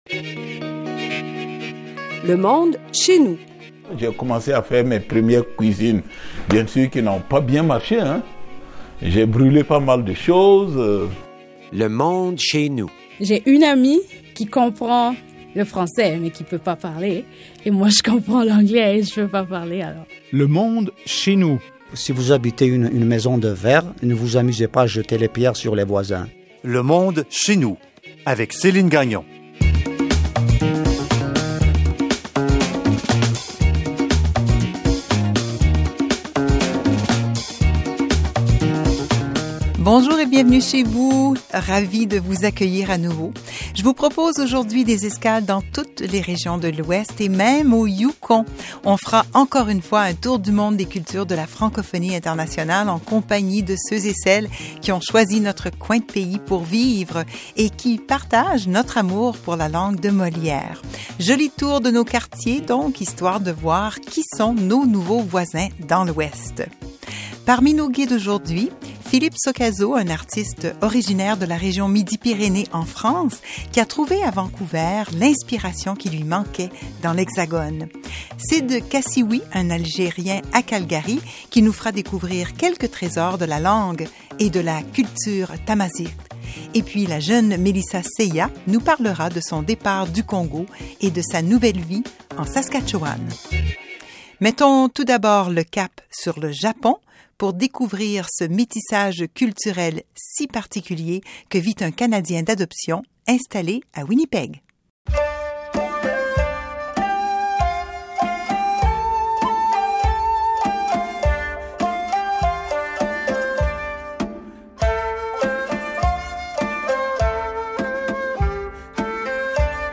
Le monde chez nous, c’est un tour du monde culturel au cœur de la francophonie de l’Ouest. C’est un rendez-vous incontournable pour découvrir, dans la bonne humeur, les voix et les accents de ceux et celles qui enrichissent ces communautés.